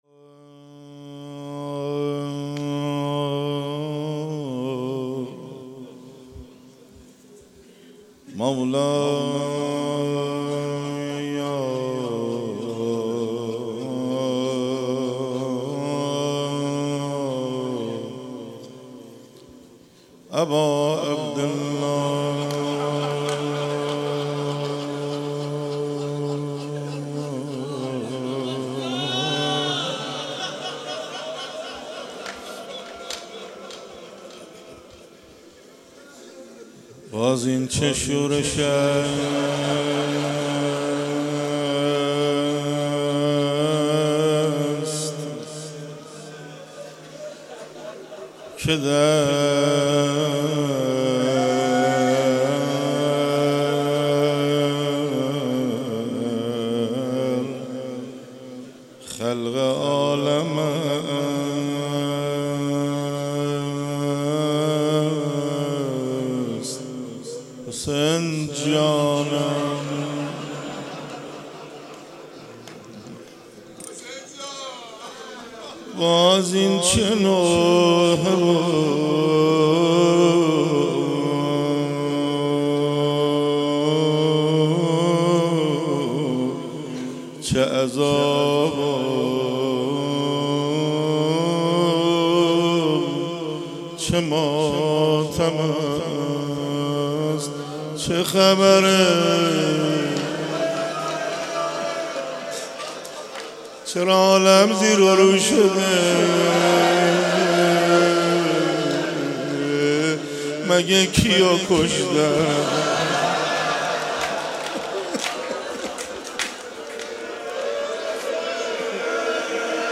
مداحی شب اول محرم
در حسینیه نور البکا- کربلای معلی